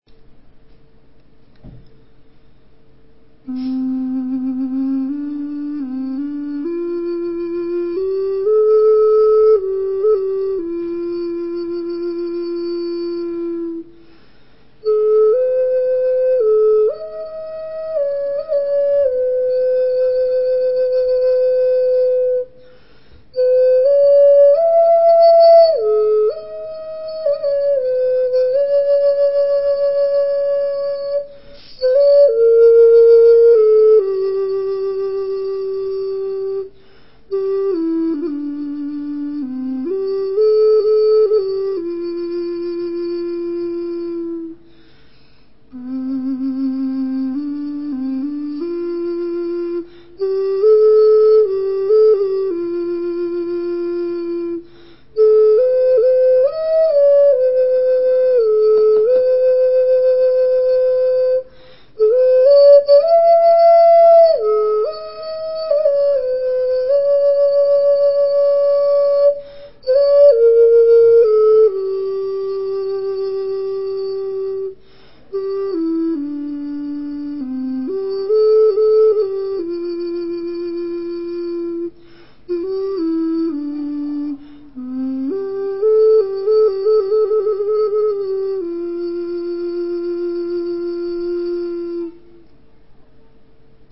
埙